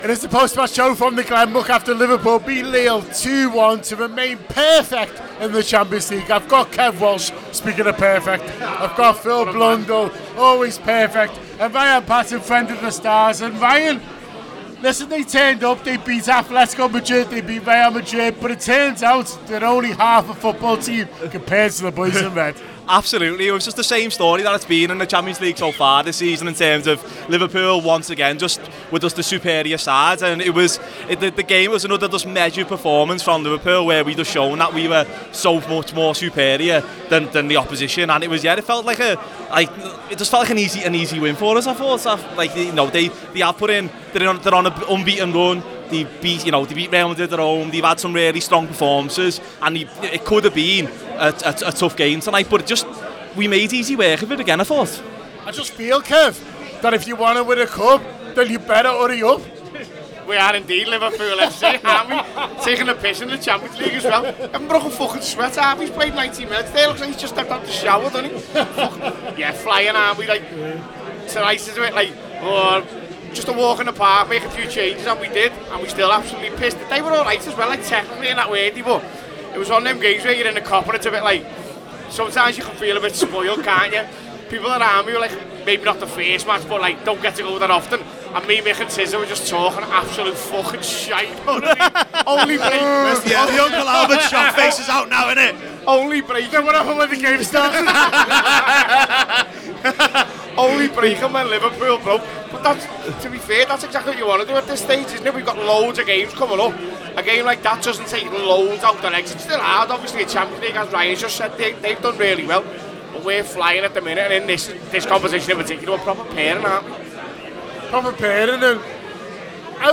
The Anfield Wrap’s post-match reaction podcast after Liverpool 2 Lille 1 in the Champions League at Anfield.